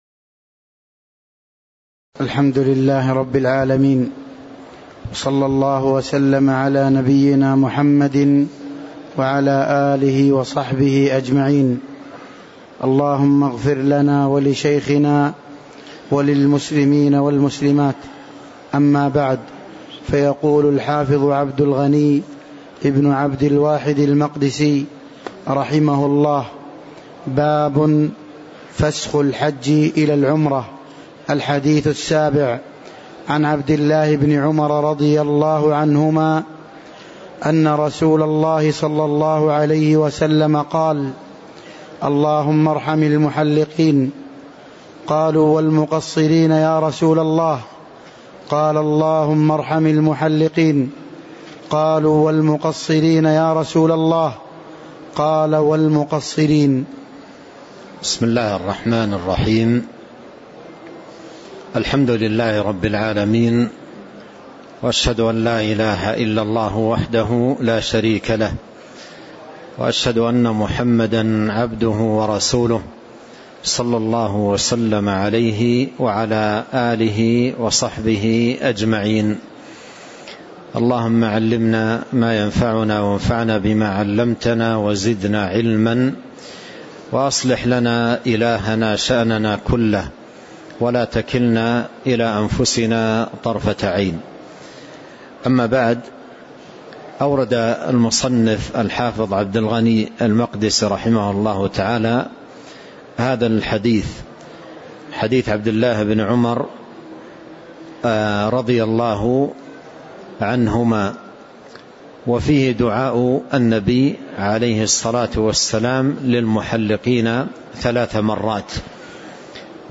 تاريخ النشر ١٨ جمادى الآخرة ١٤٤٤ هـ المكان: المسجد النبوي الشيخ